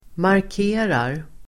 Uttal: [mark'e:rar]